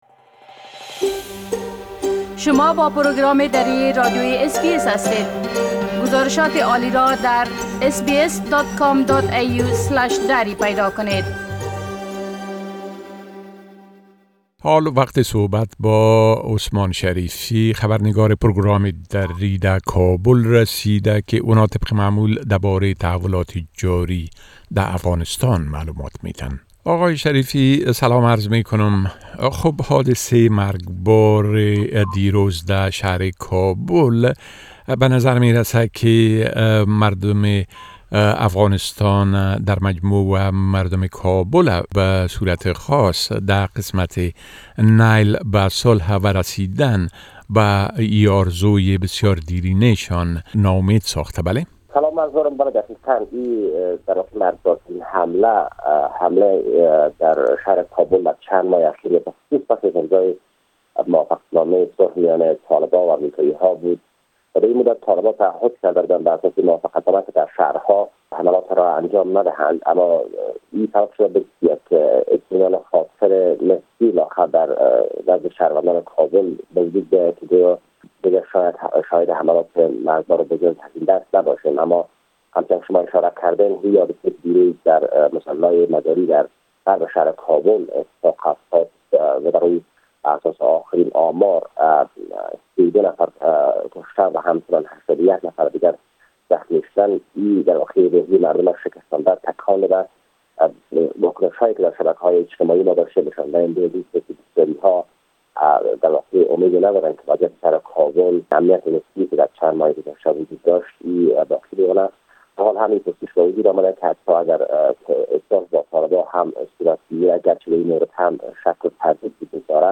گزارش كامل خبرنگار ما در كابل بشمول اوضاع امنيتى٬ و تحولات مهم ديگر در افغانستان را در اينجا شنيده ميتوانيد.